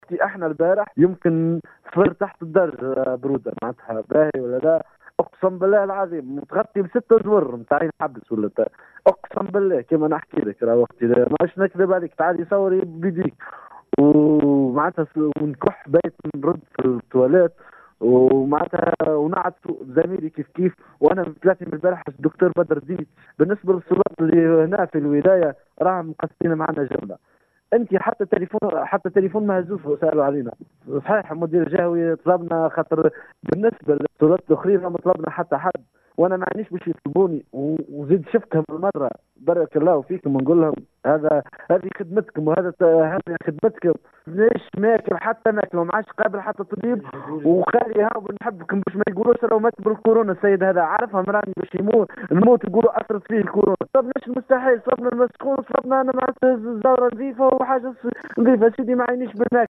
أحد-المصابين.mp3